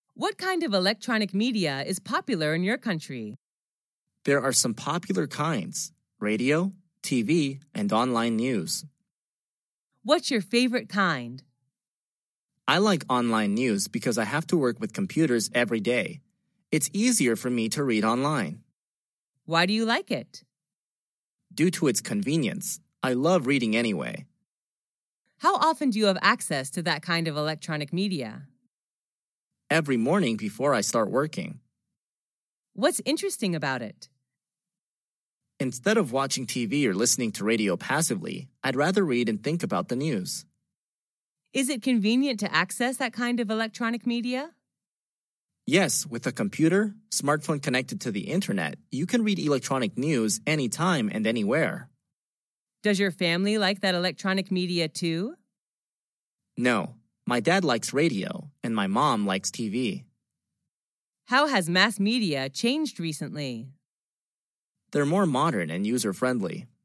Sách nói | QA-43